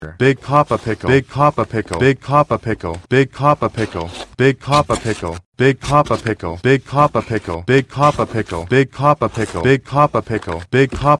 big-papa-pickle-made-with-Voicemod.mp3